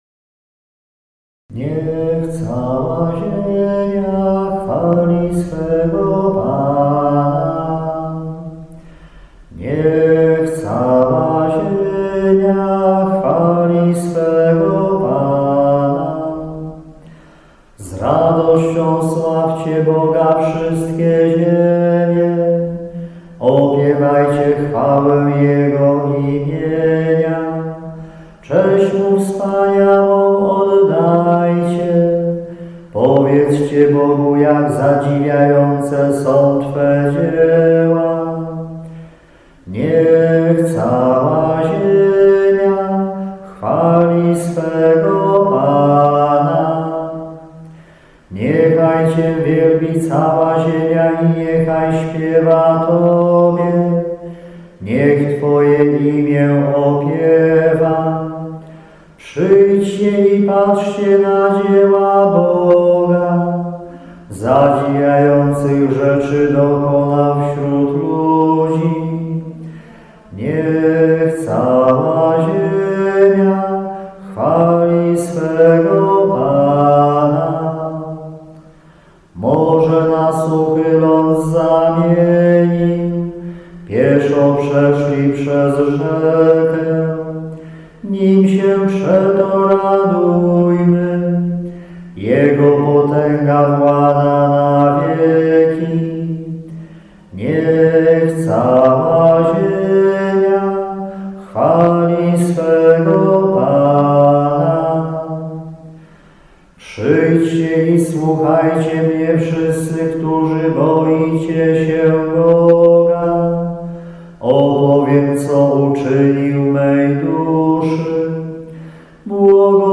psalm_responsoryjny_szosta_wielkanocna.mp3